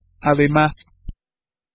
Ääntäminen
IPA : /bəˈsaɪdz/ IPA : /biˈsaɪdz/